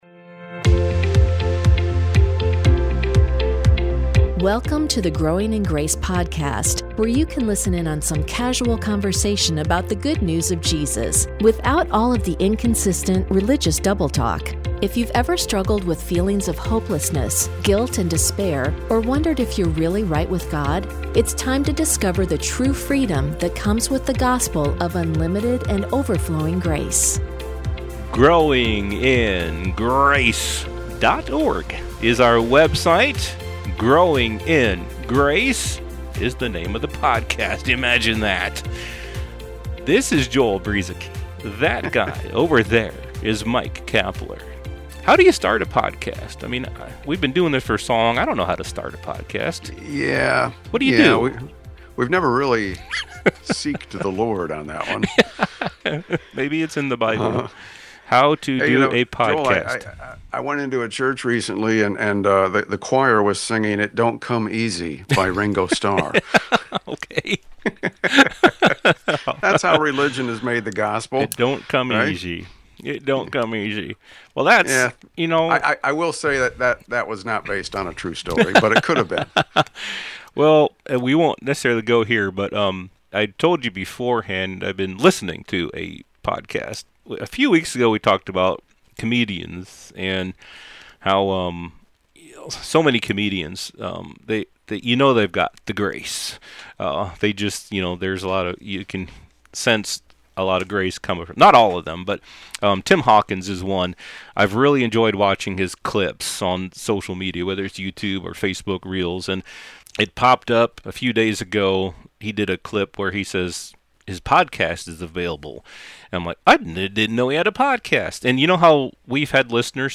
Growing in Grace is a weekly program featuring informal conversation to help with growth in understanding the gospel, and to live in the freedom that comes through Jesus Christ.